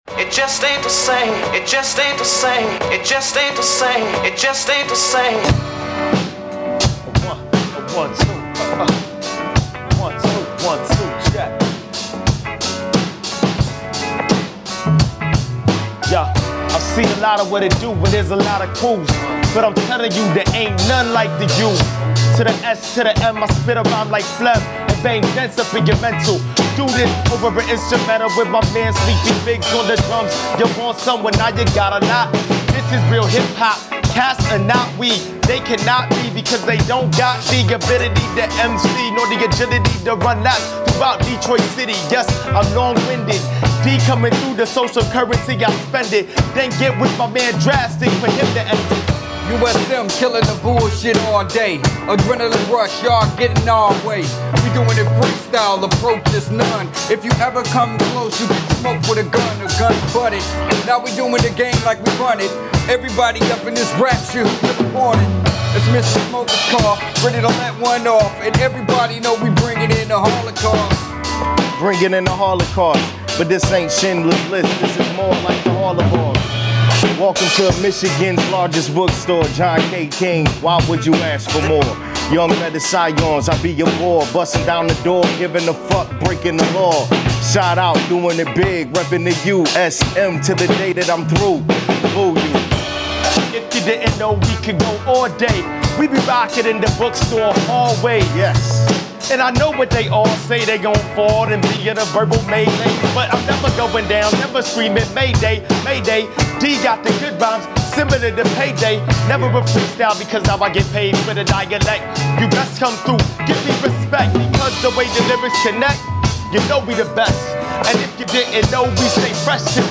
A capella performances are given, we watch with smiles.